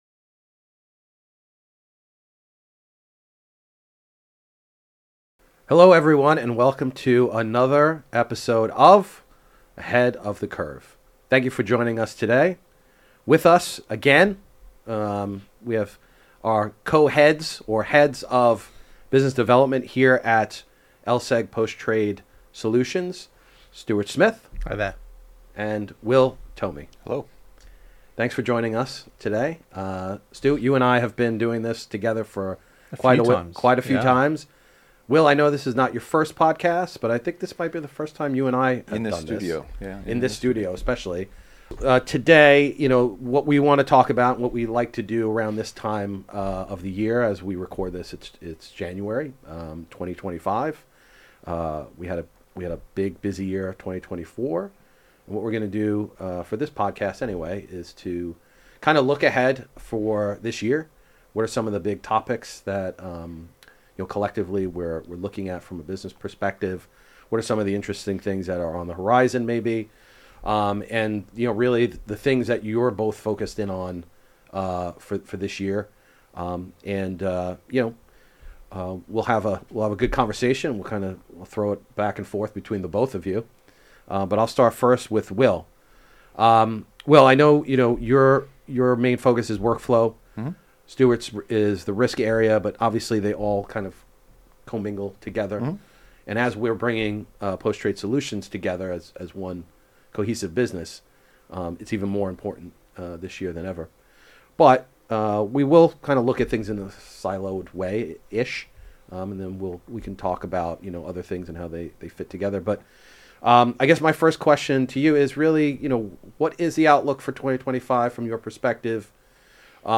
In this episode, industry experts explore key market developments shaping the financial landscape in 2025.